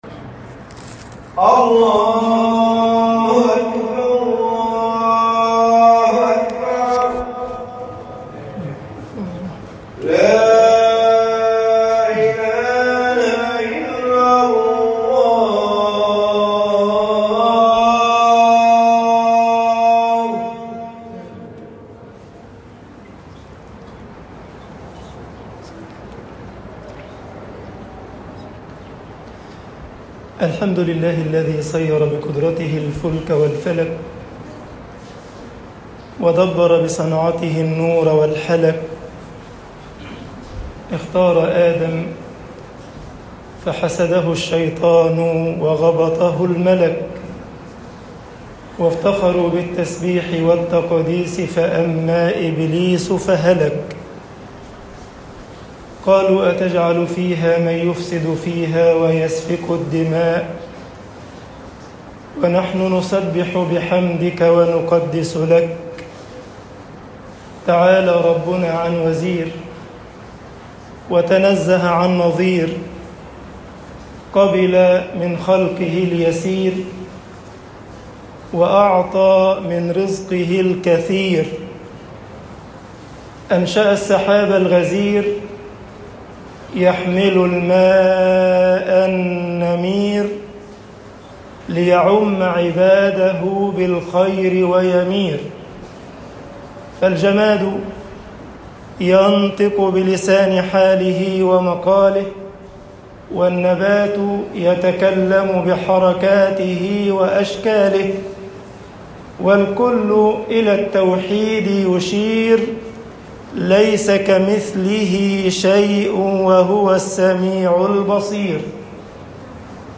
خطب الجمعة - مصر بعض العطاء فتنه طباعة البريد الإلكتروني التفاصيل كتب بواسطة